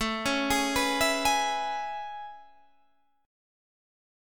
AM9 Chord